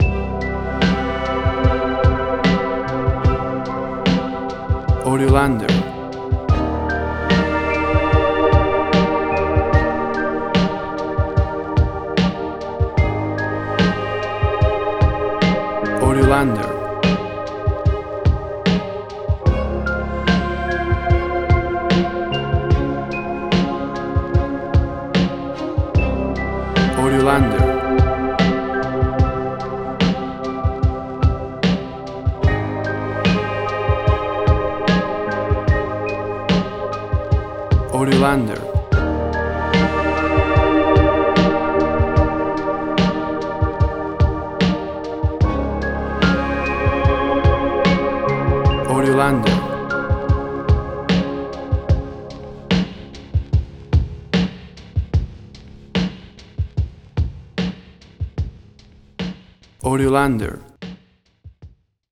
Teip hop style, ambiental, slow and deep.
Tempo (BPM): 74